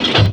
bomb_drop.wav